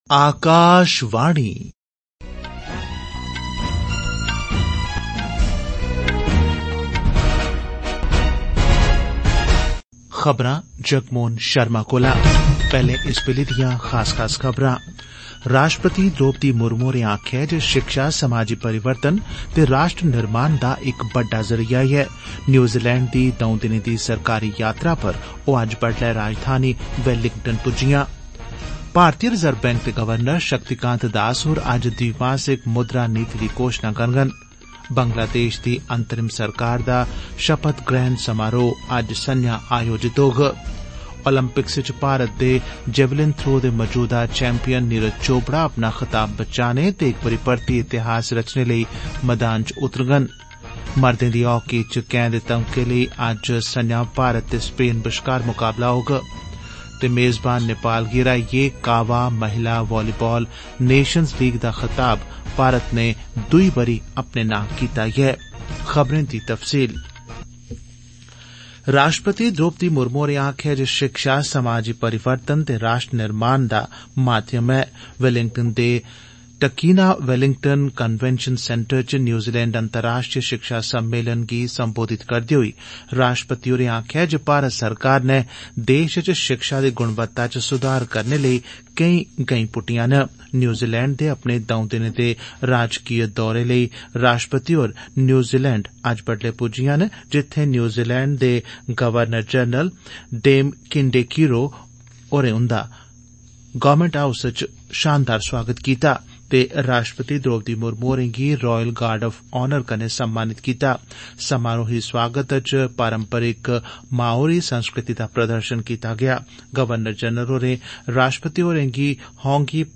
AUDIO-OF-NSD-MORNING-DOGRI-NEWS-B.mp3